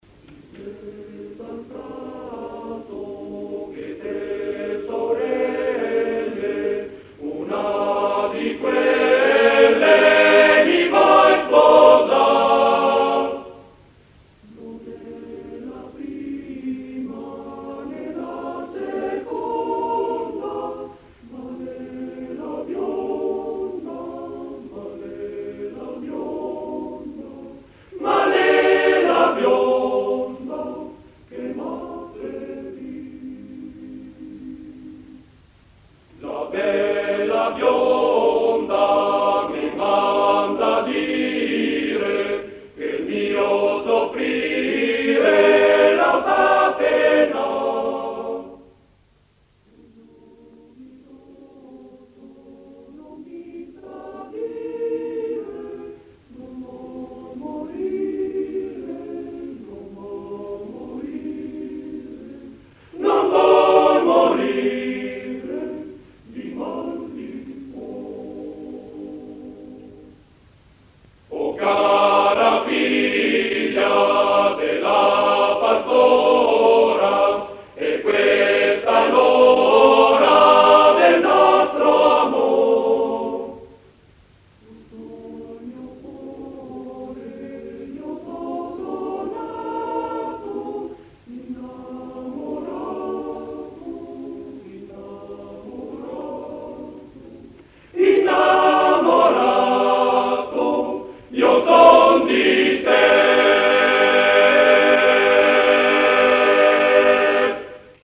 Musica > Canti
[ voci virili ]
(Trentino)